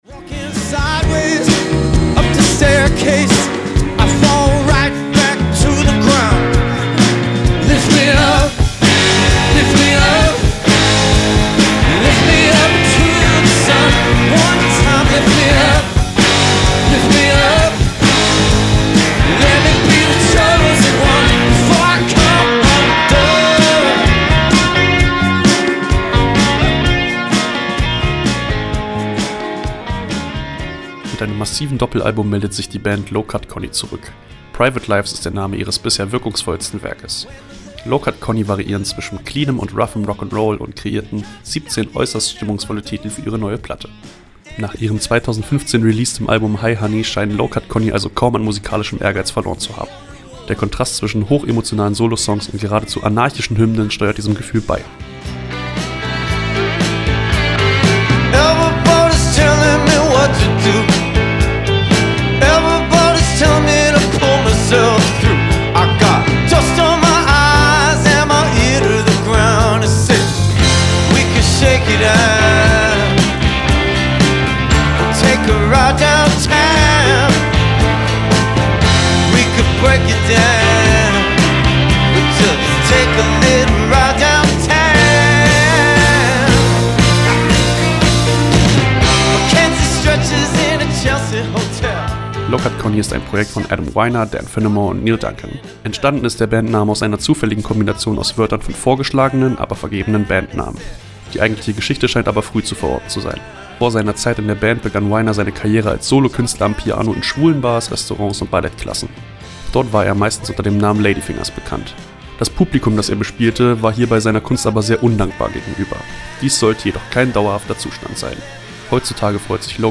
variieren zwischen cleanem und roughem Rock ‚n‘ Roll